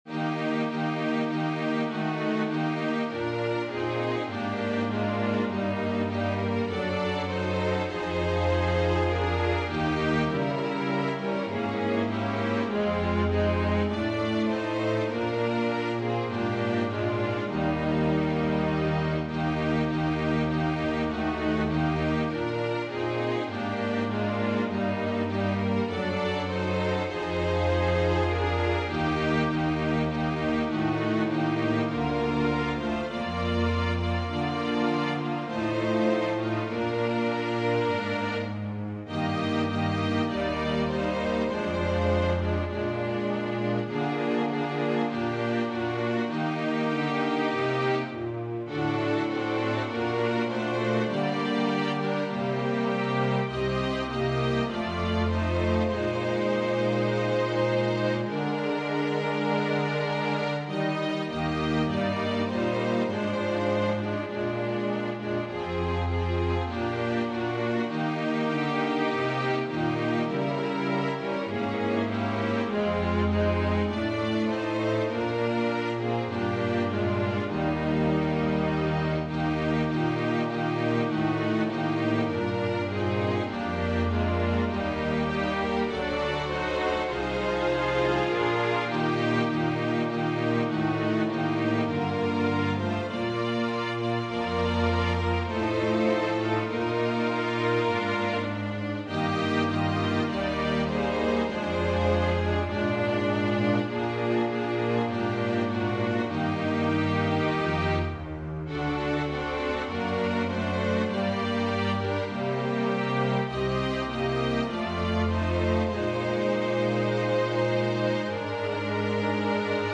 Voicing/Instrumentation: Organ/Organ Accompaniment We also have other 2 arrangements of " The Light Divine ".